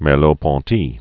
(mĕr-lōɴ-tē), Maurice 1908-1961.